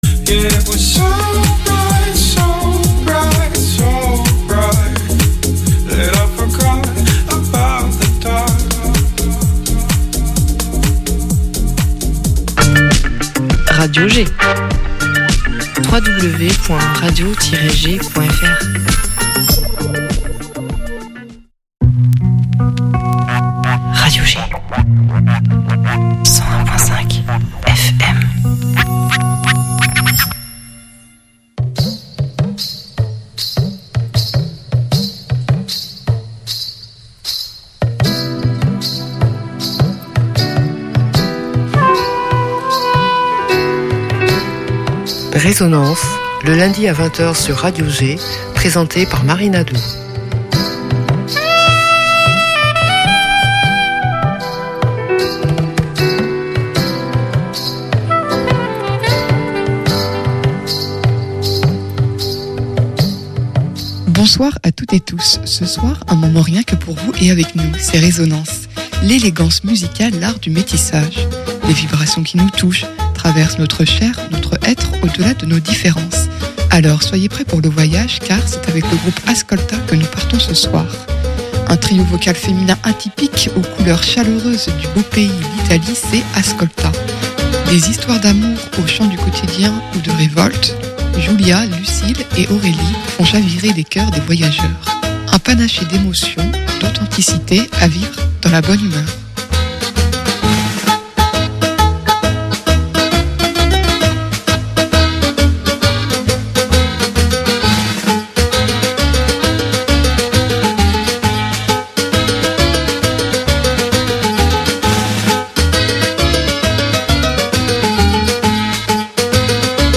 Un trio vocal féminin atypique aux couleurs du Beau Pays, l'Italie, c'est Ascolta.